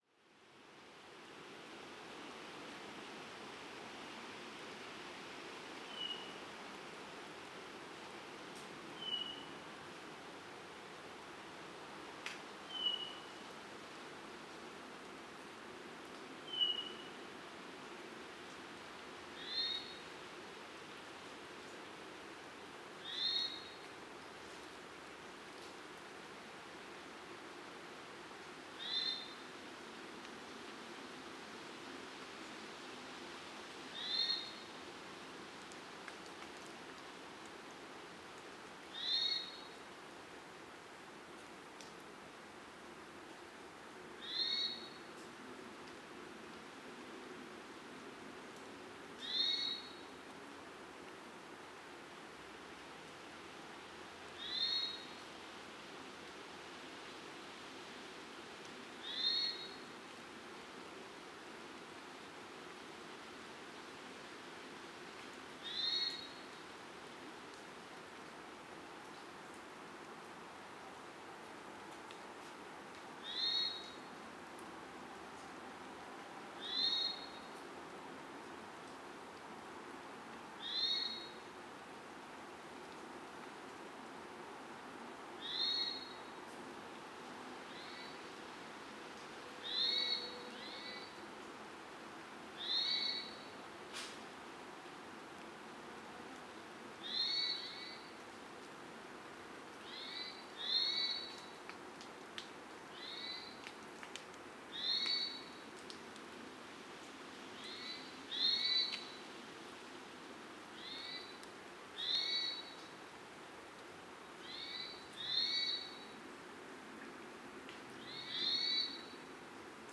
Hermit Thrush – Catharus guttatus
SAND DUNES OF TADOUSSAC – Dusk Chorus Recording of the forest at dusk. Species : Hermit Thrush and Swainson’s Thrush.